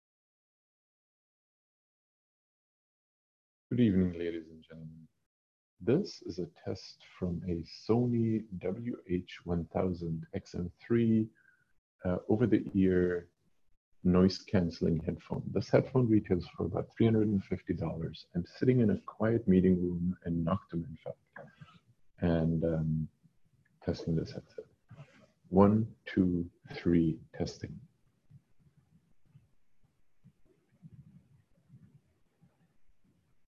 But even products like the Sony WH-1000xm3 do poorly here, because call quality is not their primary focus and the microphones are optimized to enable the ANC feature.